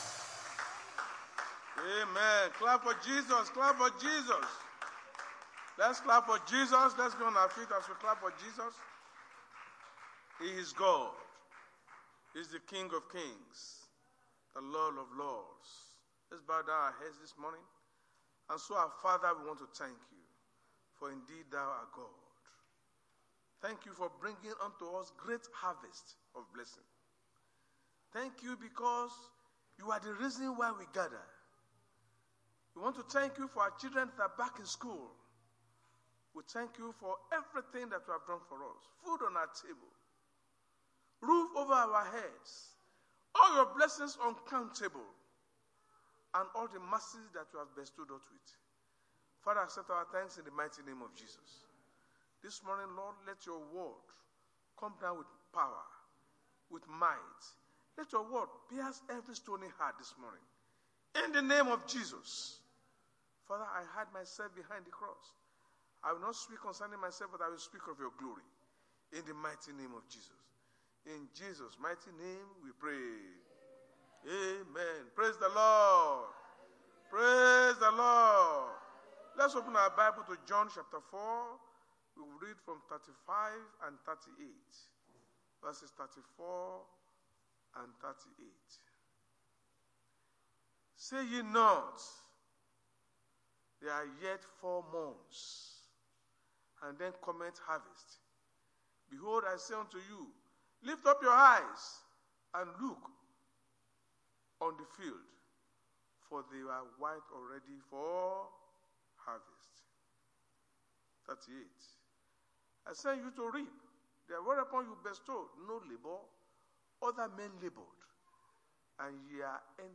RCCG House Of Glory Sunday Sermon: Harvest Of Blessings
Service Type: Sunday Church Service